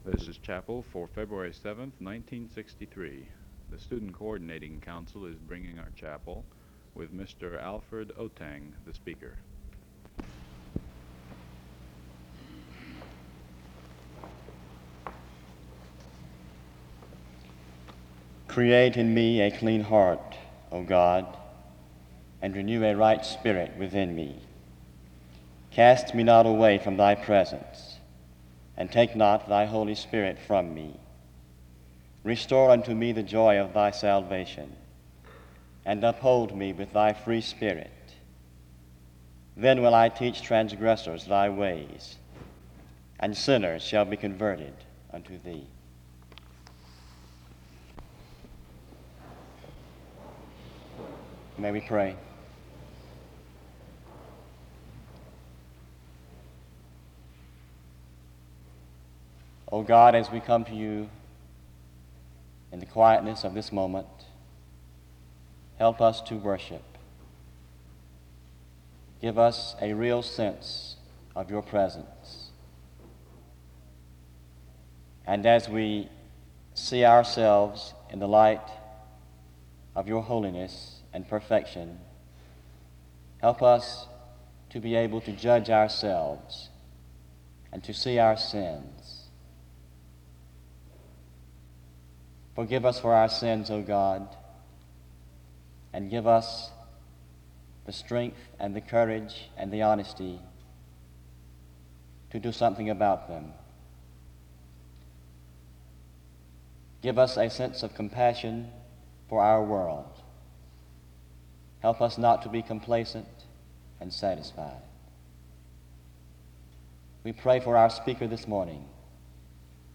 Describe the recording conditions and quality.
SEBTS Chapel The service begins with an opening prayer from 0:18-2:35. The source texts, Matthew 9:35-38 and Matthew 28:19-20 were read, and the speaker was introduced from 2:50-5:50. This service was organized by the Student Coordinating Council.